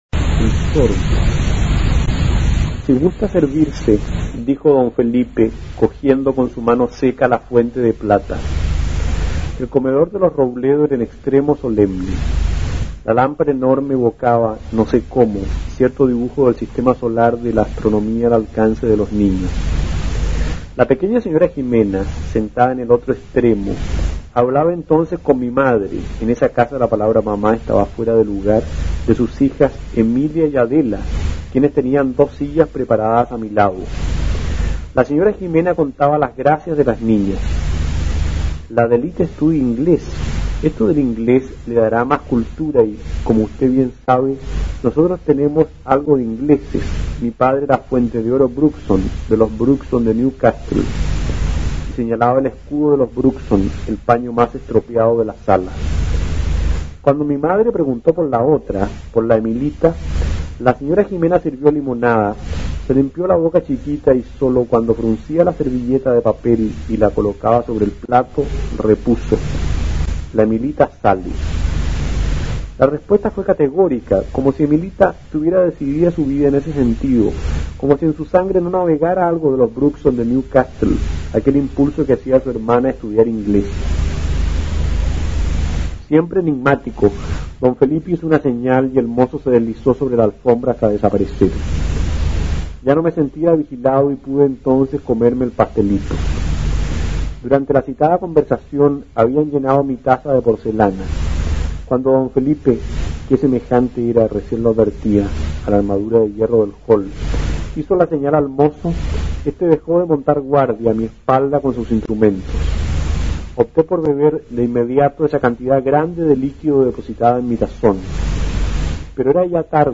Aquí podrás oír al escritor chileno Carlos Ruiz-Tagle (1932-1991) leyendo el cuento El Sorbo, una de sus características narraciones cargadas de humor y absurdo.
Narración